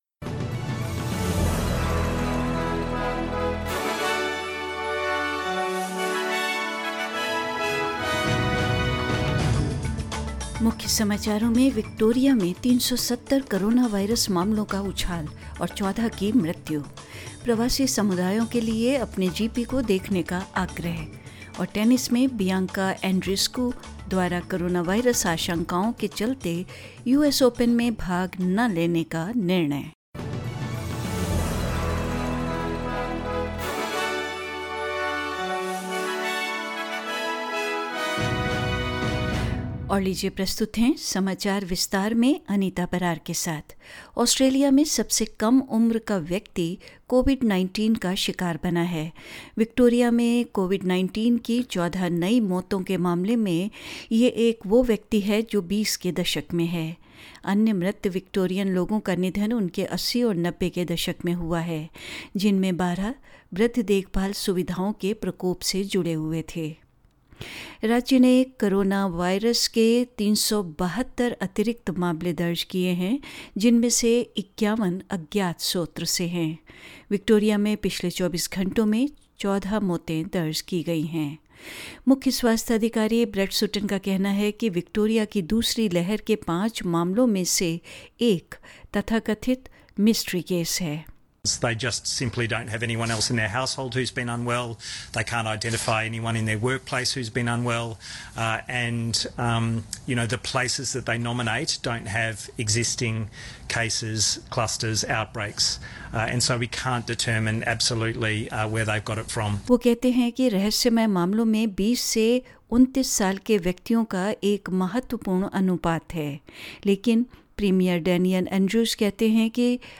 News in Hindi 14th August 2020